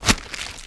STEPS Soft Plastic, Walk 01.wav